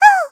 文件 文件历史 文件用途 全域文件用途 Chorong_atk_01.ogg （Ogg Vorbis声音文件，长度0.3秒，191 kbps，文件大小：8 KB） 源地址:地下城与勇士游戏语音 文件历史 点击某个日期/时间查看对应时刻的文件。